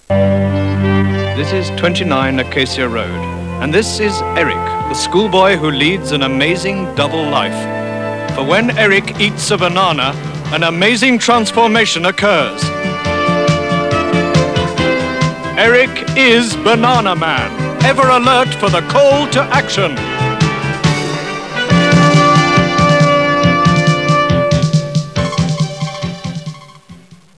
theme music